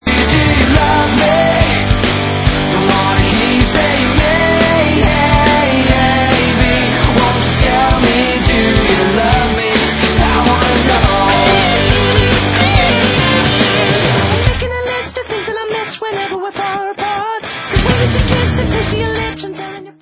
britští poprockeři